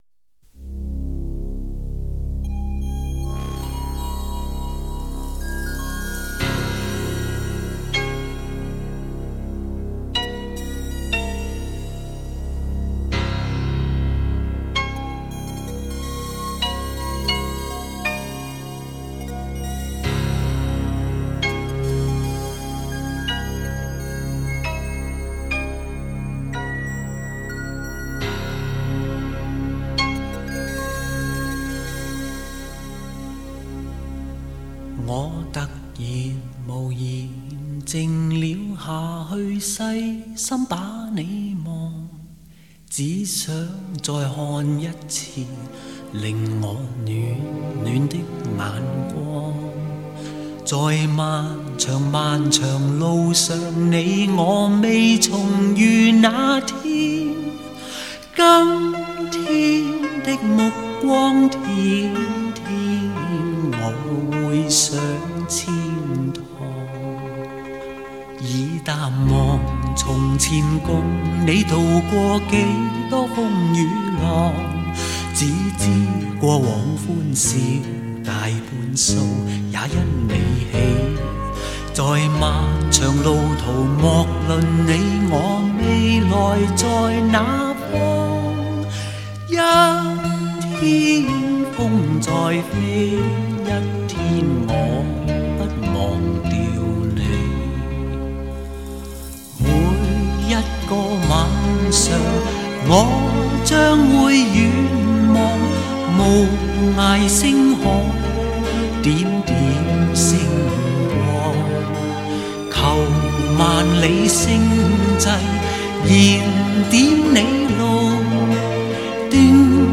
无损音乐